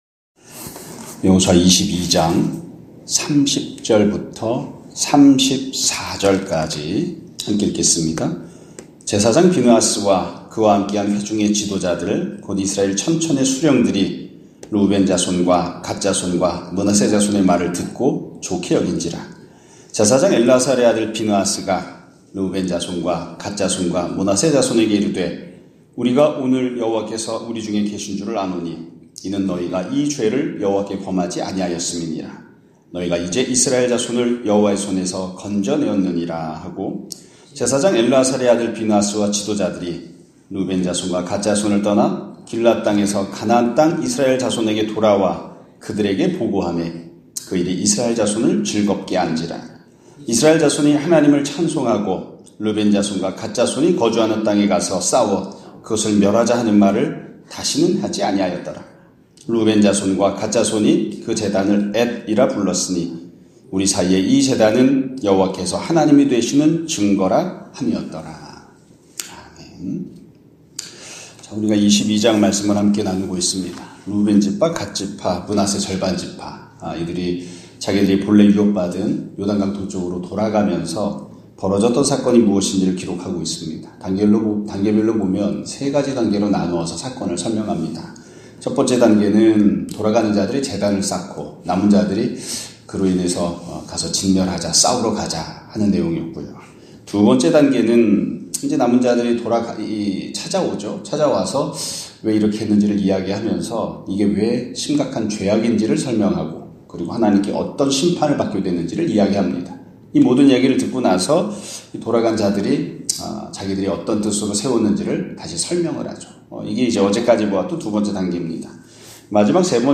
2025년 2월 13일(목요일) <아침예배> 설교입니다.